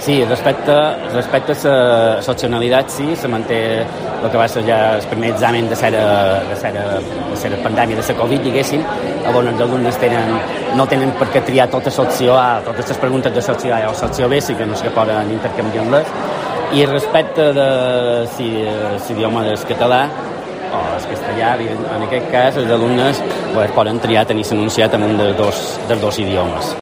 Corte del Informativo Mediodía